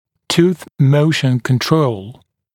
[tuːθ ‘məuʃn kən’trəul][ту:с ‘моушн кэн’троул]контроль движения зуба